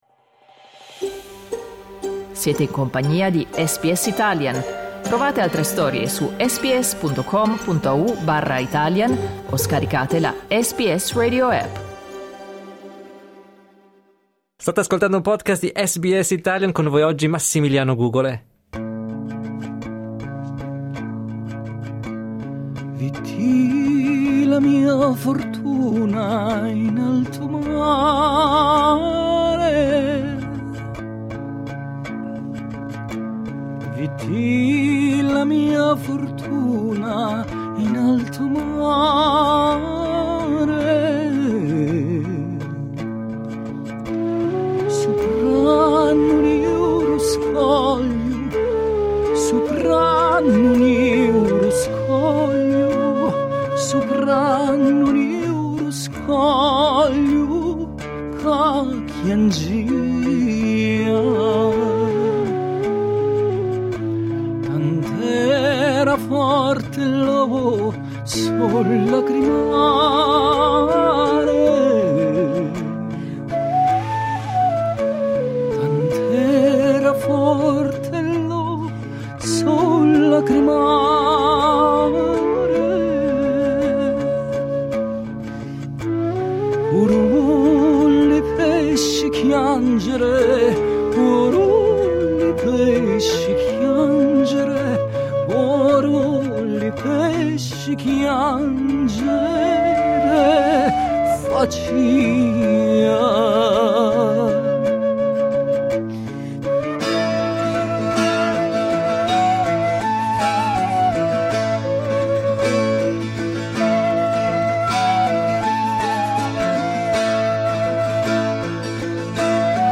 La Cantiga De La Serena è un trio pugliese che riscopre la musica tradizionale del Mediterraneo, attualmente in tour in Australia. Ascolta, in questo podcast, la loro musica ed un'intervista in cui si raccontano.
La Cantiga De La Serena in visita agli studi di Sydney di SBS